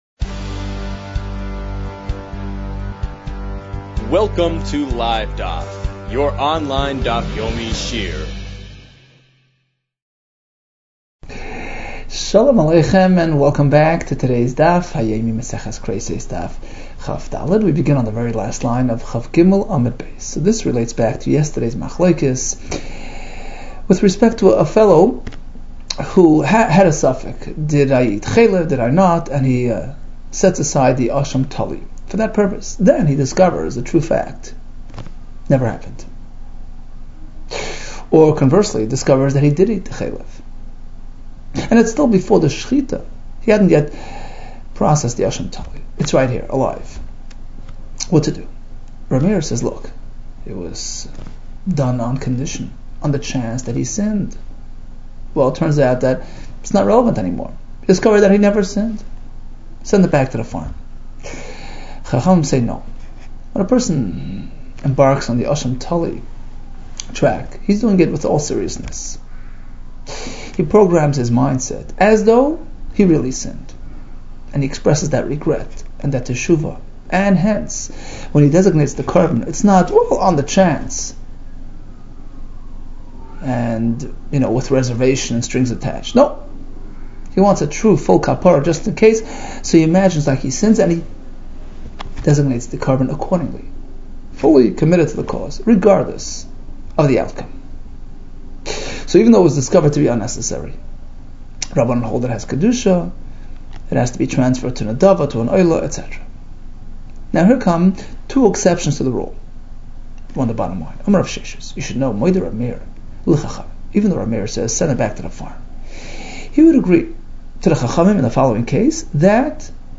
Kereisos 23 - כריתות כג | Daf Yomi Online Shiur | Livedaf